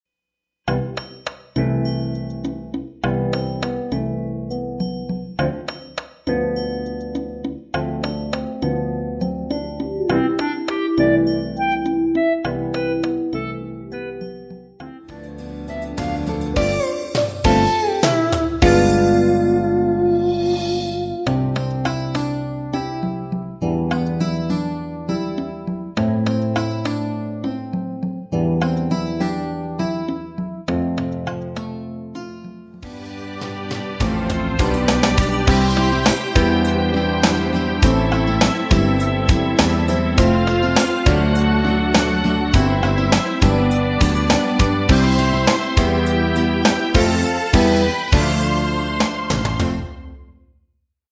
엔딩이 페이드 아웃이라노래 부르시기 편하게 엔딩을 만들어 놓았습니다. 발매일 1994.06 키 C 가수